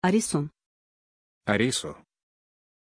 Pronunciation of Arisu
pronunciation-arisu-ru.mp3